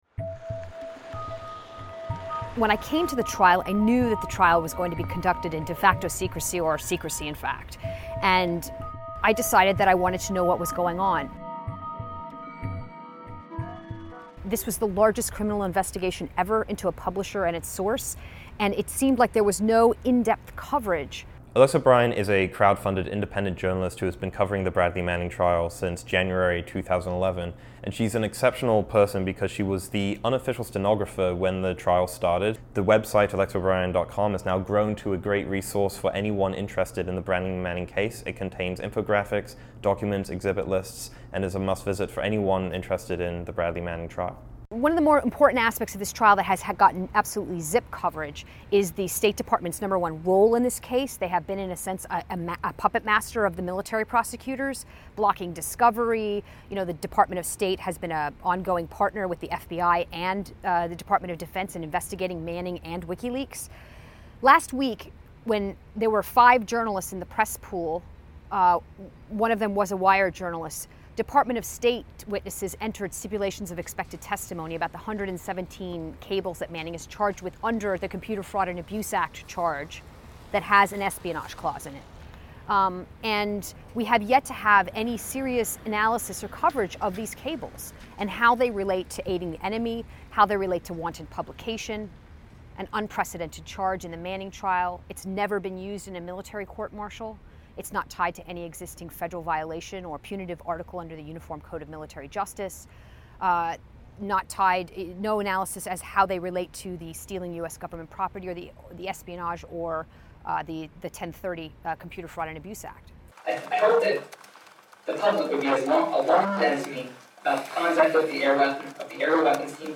With the exception of one leaked recording of Manning's testimony (part of which is included in Reason's video), all recordings of the courtroom have been forbidden.